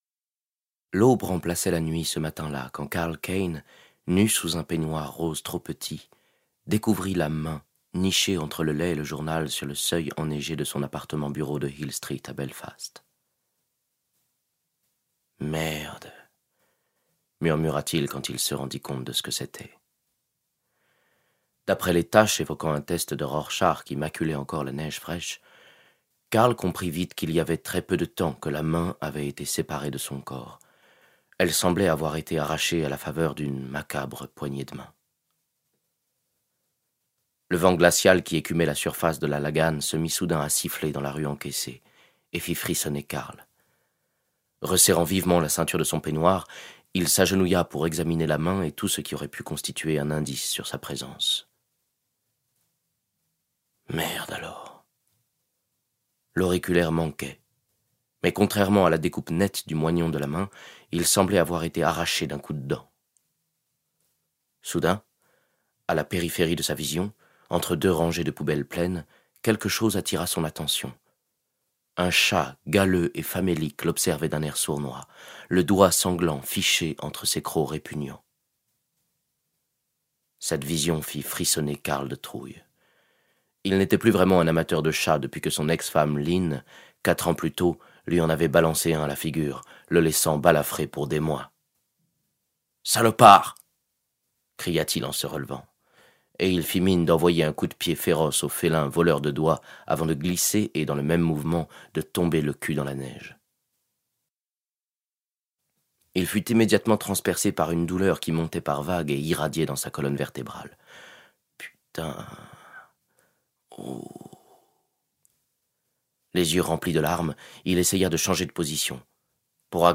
Lire un extrait - Un sale hiver de Sam Millar